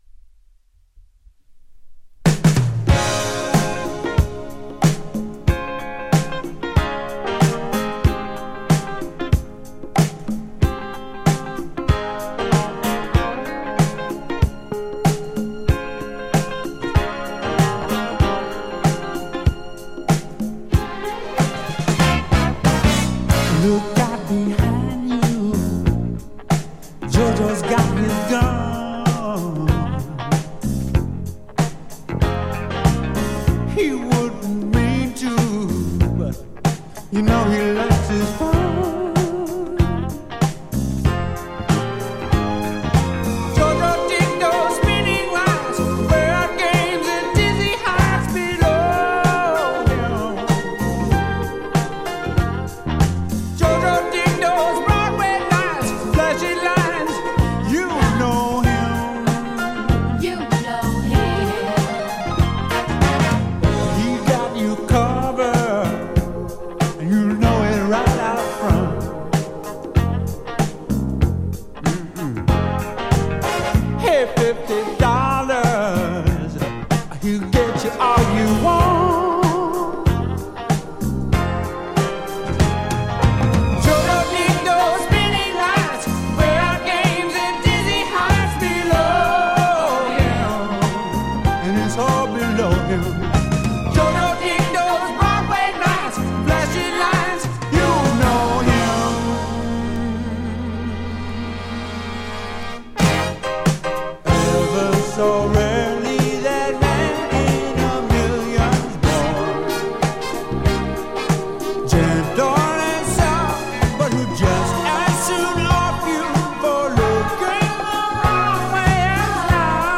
ジャンル(スタイル) AOR / MELLOW GROOVE / SOUL / DISCO